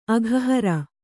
♪ aghahara